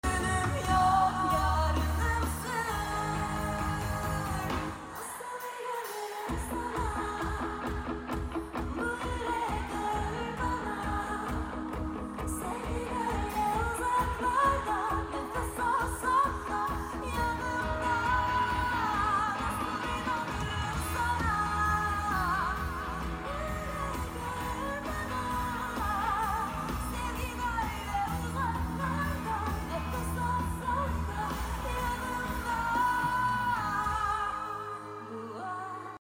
Solo part - Harbiye konseri